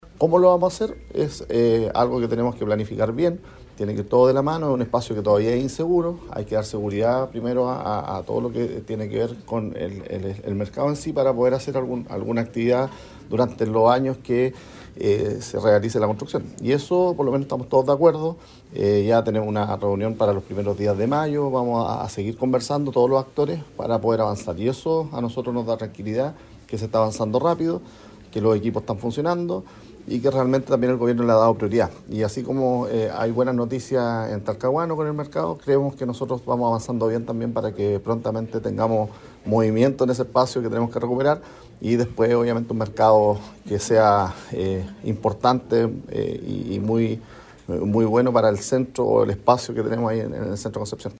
El alcalde de Concepción, Héctor Muñoz, sostuvo que “las cosas van avanzando, y hoy día en la reunión por lo menos avanzó en varias cosas, primero que se tiene que expropiar y la expropiación no tiene que ir solamente con los recursos, sino que tiene que ir de la mano para recuperar ese espacio mientras esté la construcción”.
MercadoCCP-4-Alcaldeccp.mp3